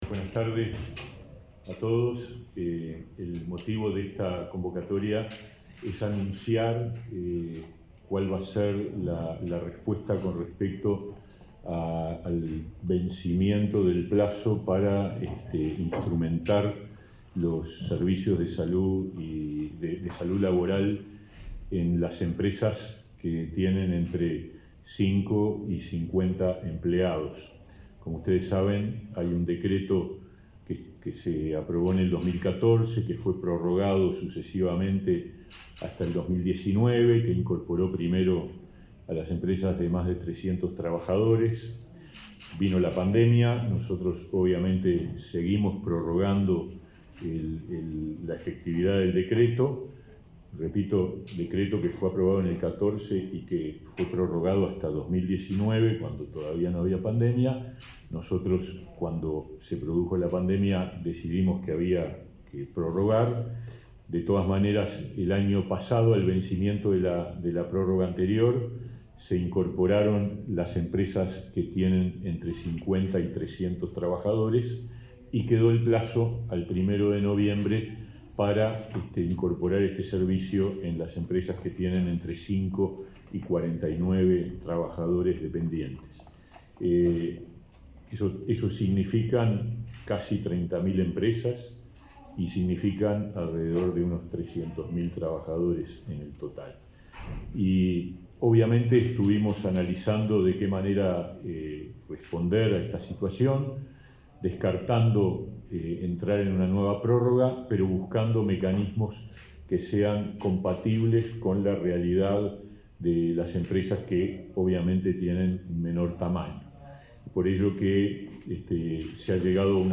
Conferencia de autoridades del Ministerio de Trabajo
El ministro Pablo Mieres y el inspector general de Trabajo y Seguridad Social, Tomás Teijeiro, presentaron las incorporaciones de un nuevo decreto